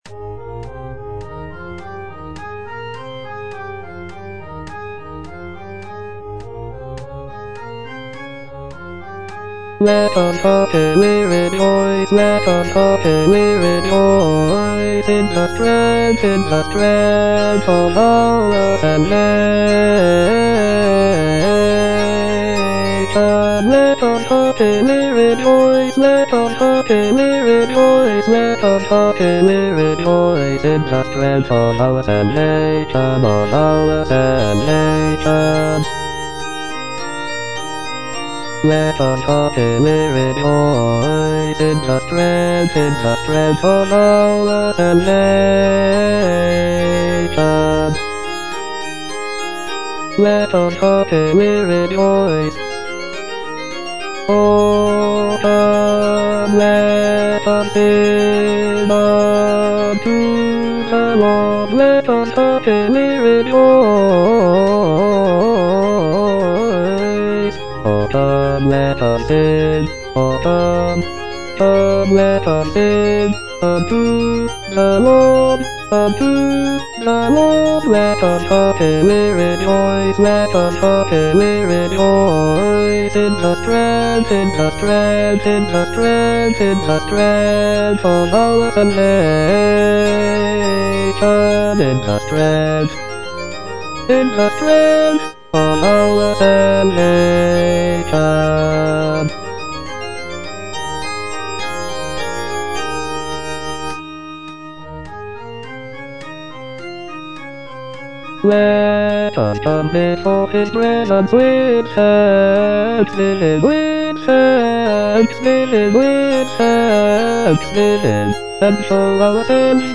(A = 415 Hz)
Bass (Voice with metronome) Ads stop
sacred choral work